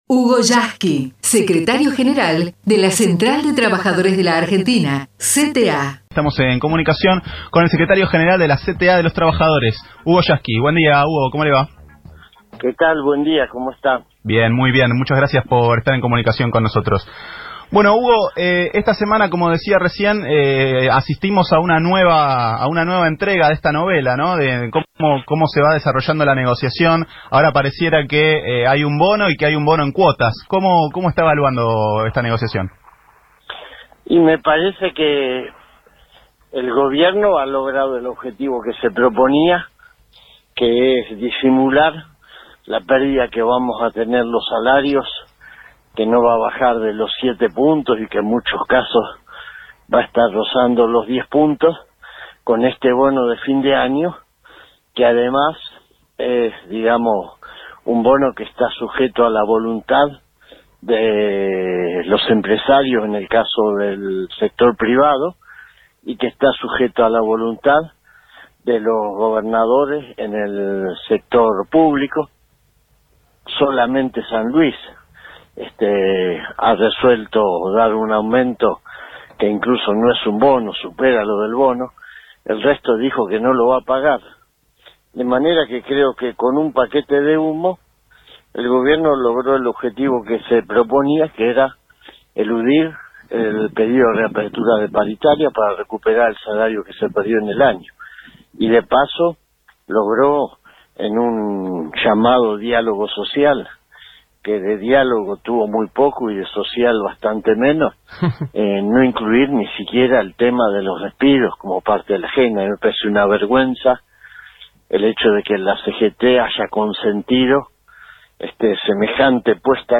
HUGO YASKY (entrevista) - Futurock FM